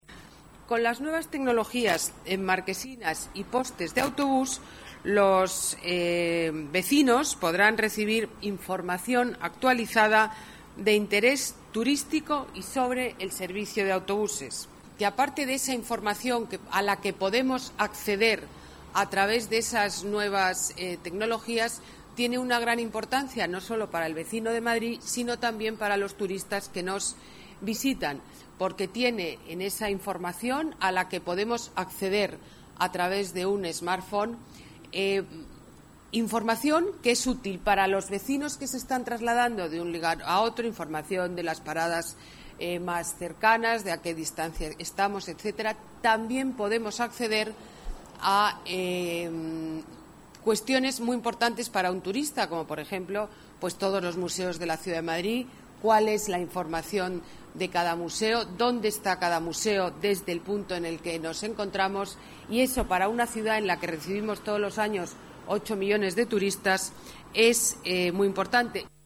Discurso Ana Botella en la presentación de marquesinas